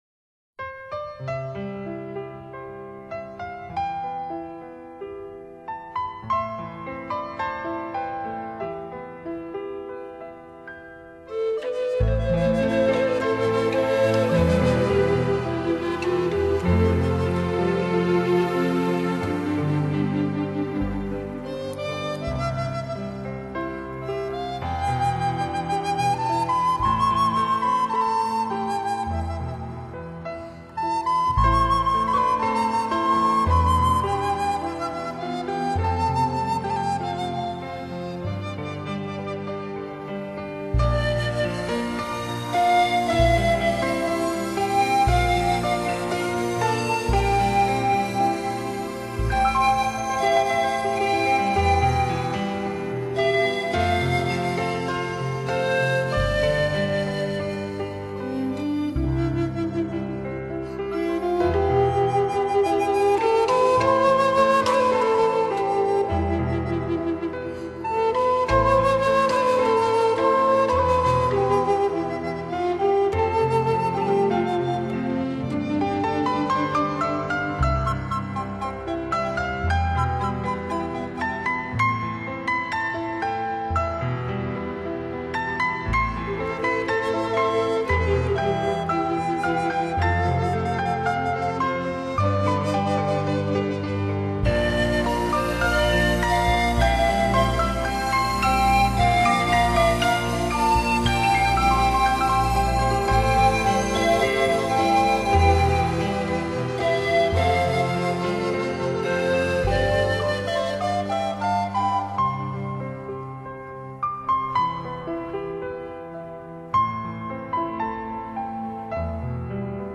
安静舒缓，流淌的乐曲里
仿佛在普罗旺斯紫色的天空下做一个温煦的梦